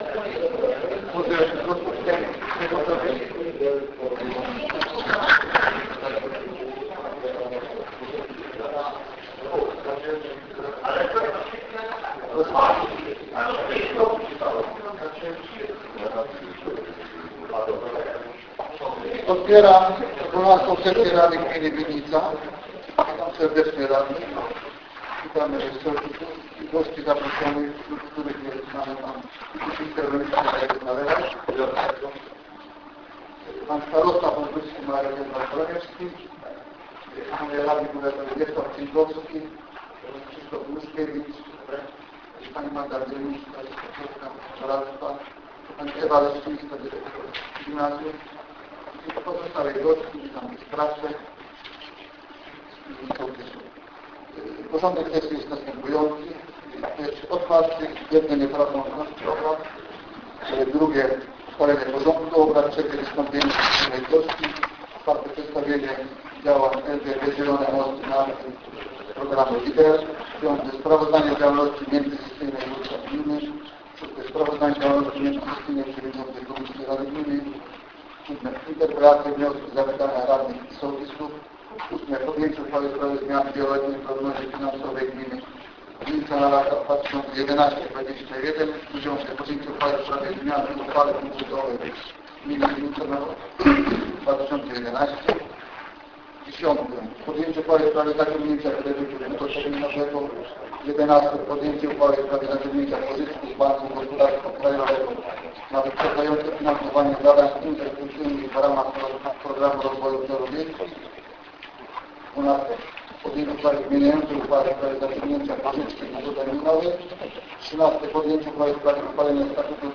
XII Sesja Rady Gminy z dnia 5 września 2011 - Urząd Gminy w Winnicy